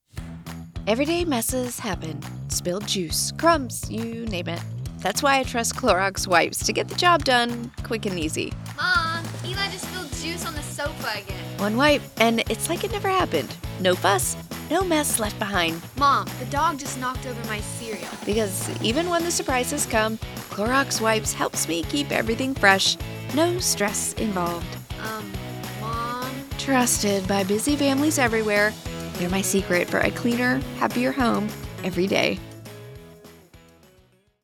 Clorox Wipes -- Relatable, Mother, Endearing, Caring, Authentic, Genuine